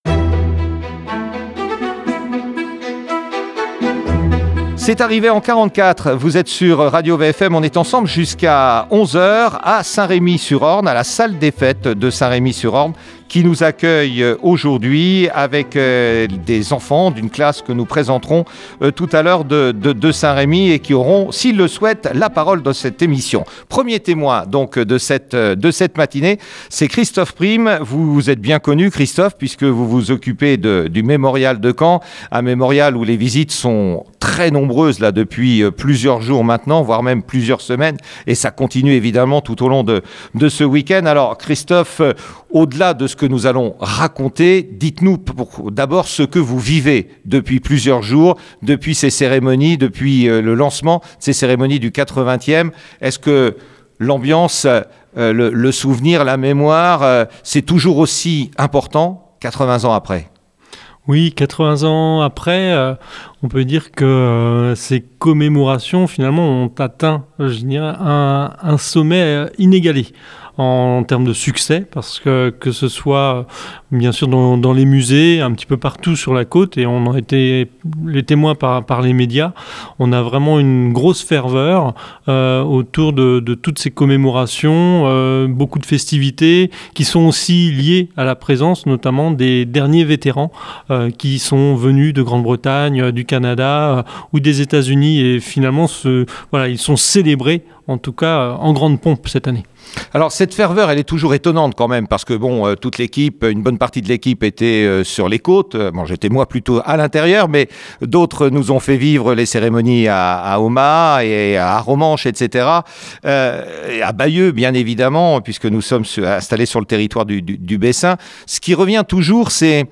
Émission exceptionnelle diffusée Dimanche 16 Juin à Saint-Remy sur Orne à 10 h 15. Témoignages, récits de la libération du Bocage.